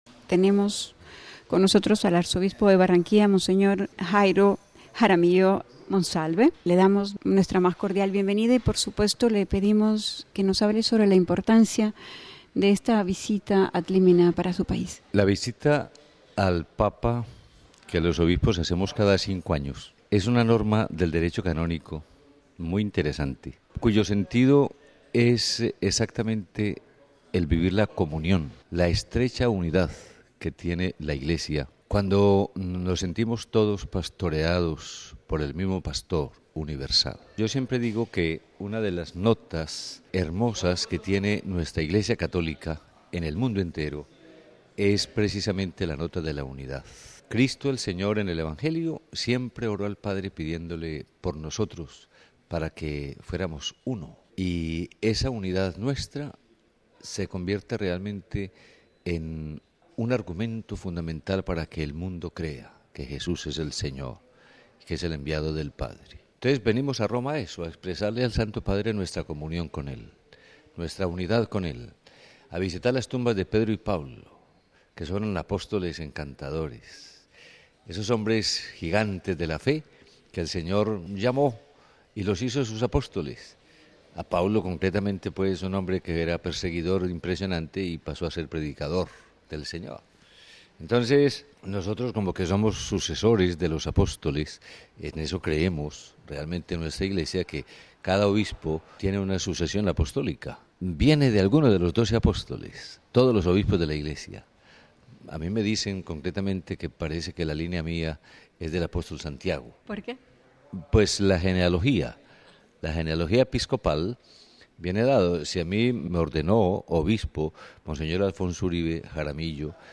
Por su parte el arzobispo de Barranquilla, Mons. Jairo Jaramillo, dijo que la visita ad limina tiene el sentido de vivir la comunión con Cristo en la estrecha unidad que tiene la Iglesia.